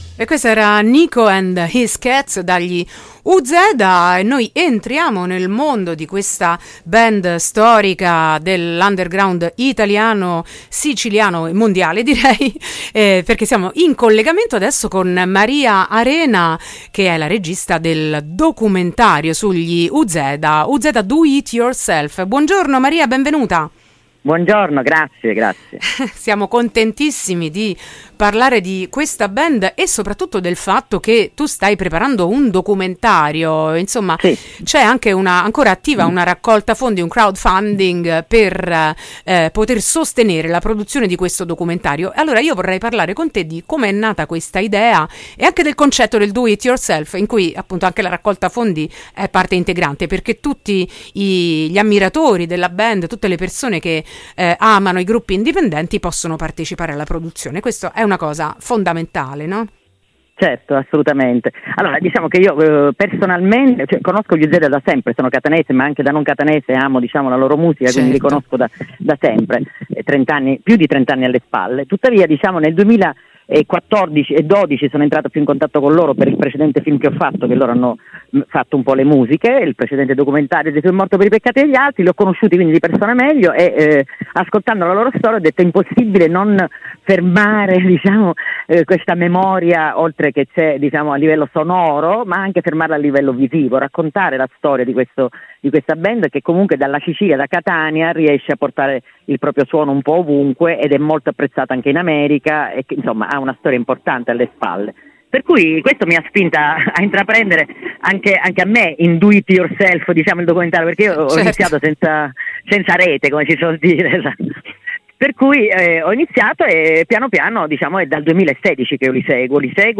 Arriva il documentario sugli Uzeda: intervista
intervista-uzeda.mp3